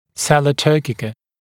[‘selə ‘tɜːkɪkə][‘сэлэ ‘тё:кикэ]турецкое седло